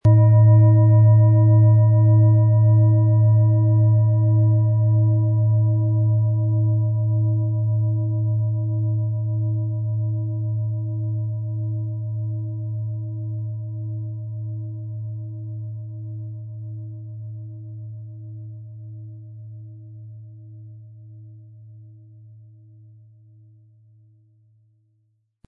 Tibetische Kopf-Herz-Fuss-Universal- und Bauch-Klangschale, Ø 25,3 cm, 1700-1800 Gramm, mit Klöppel
Im Sound-Player - Jetzt reinhören können Sie den Original-Ton genau dieser Schale anhören.
Für jemanden dem Klang vor Optik geht eine schöne Gelegenheit eine volltönende tibetische Klangschale in stattlicher Größe zu erwerben.
MaterialBronze